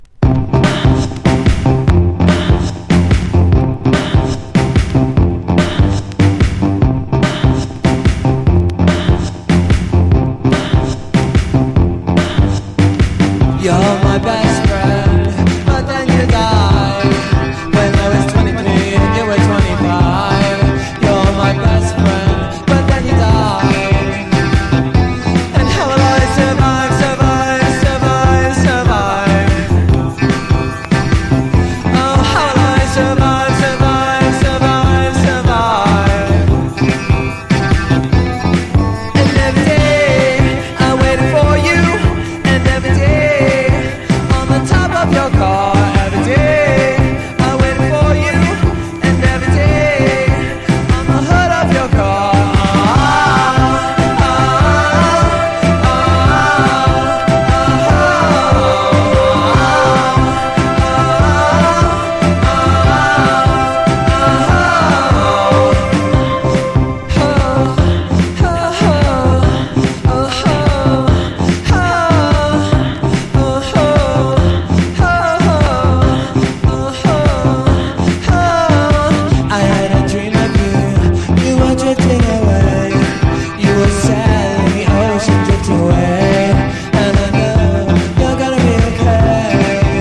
1. 10s ROCK >
NEO ACOUSTIC / GUITAR POP